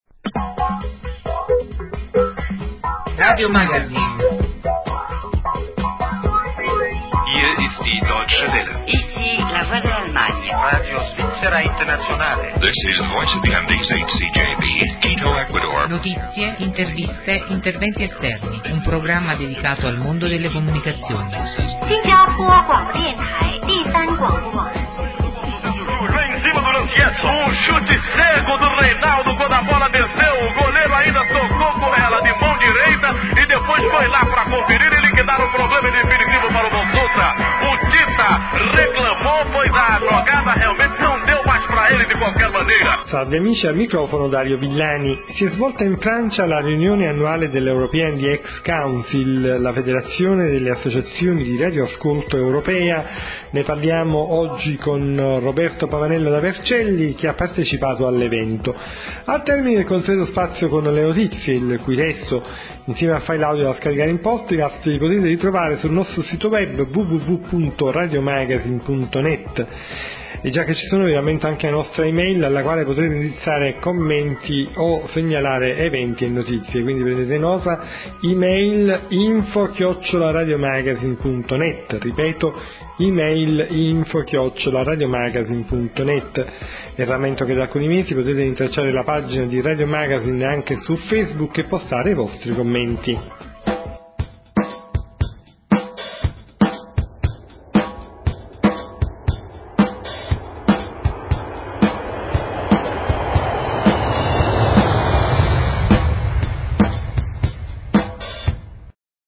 sigla